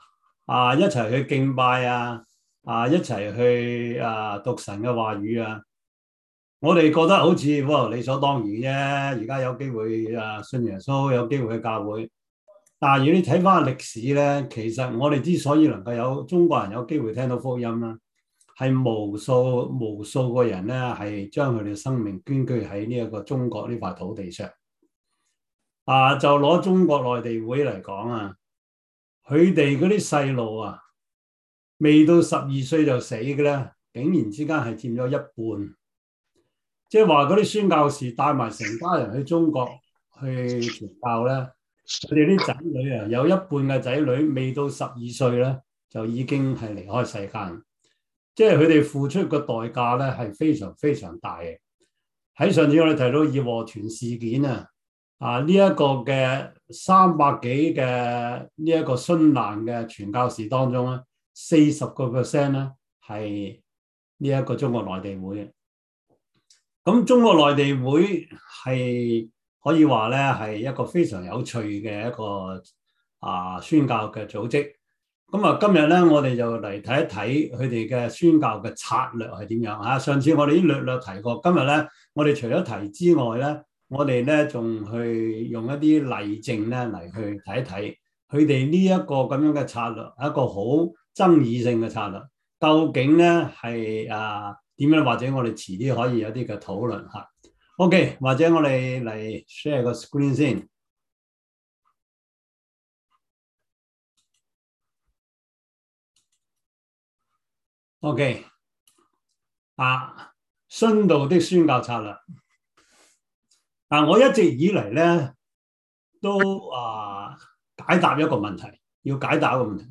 中文主日學